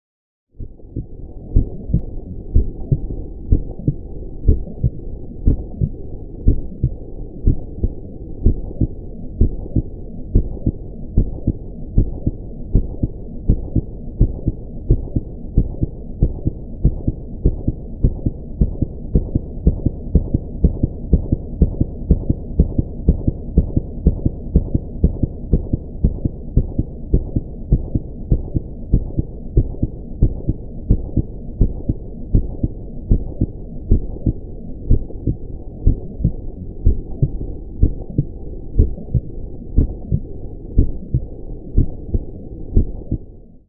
جلوه های صوتی
دانلود صدای اکو ضربان قلب در ادیت فیلم ها از ساعد نیوز با لینک مستقیم و کیفیت بالا